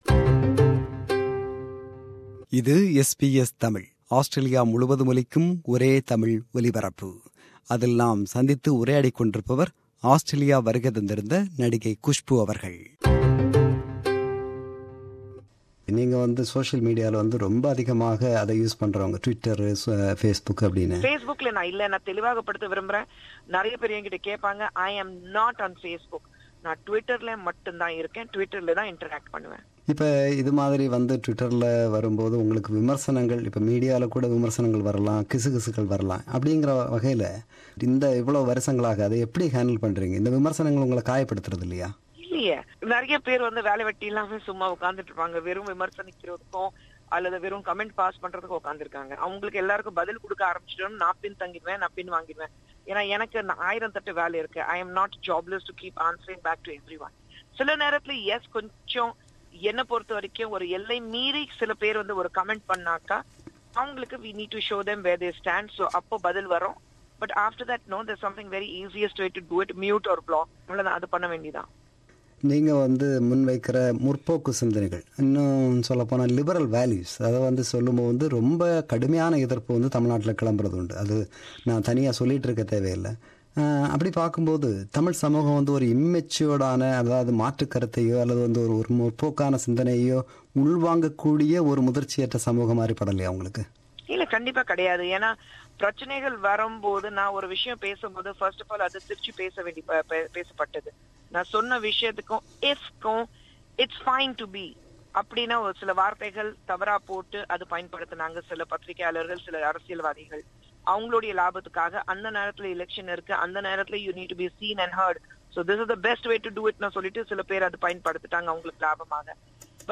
Interview with Kushboo – Part 2